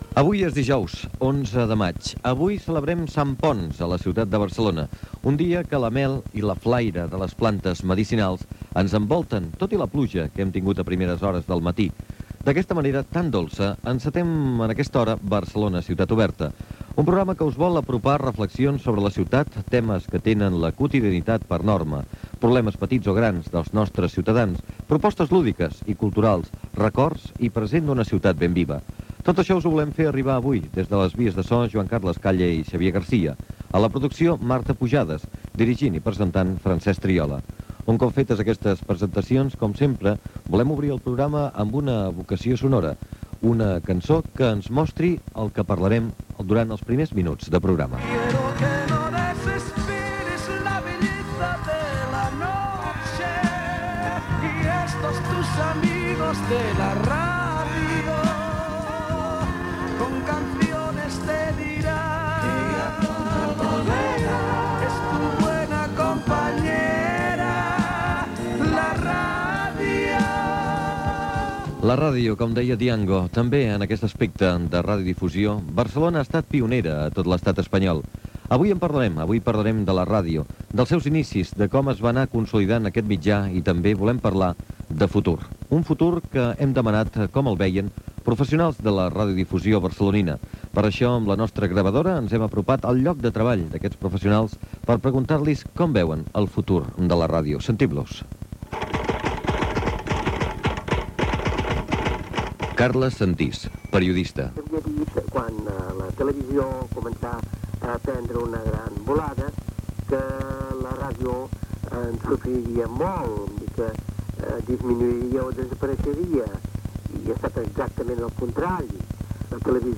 Entrevista sobre els inicis de la ràdio a Barcelona
Gènere radiofònic Info-entreteniment